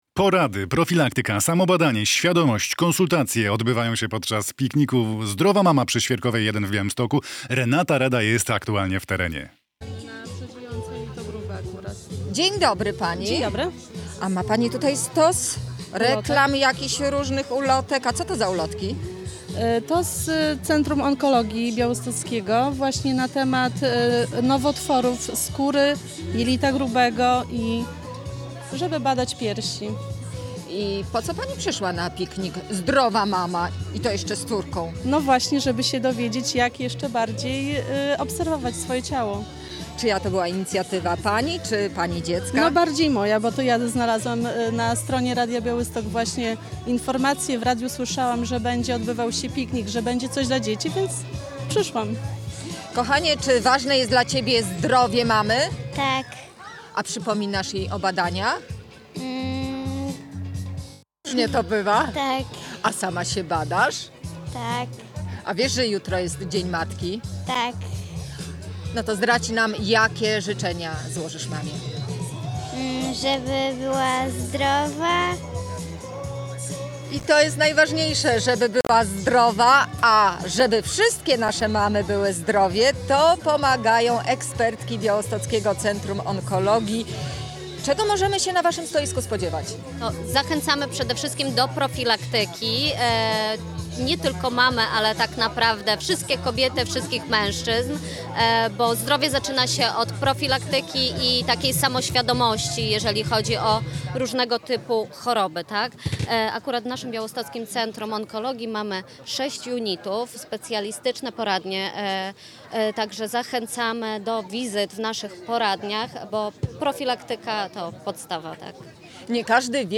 Z ekspertkami z BCO